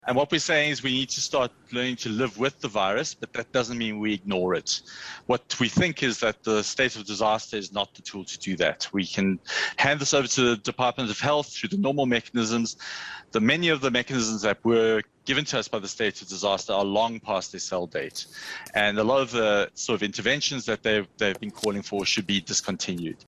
spoke to eNCA and had this to say.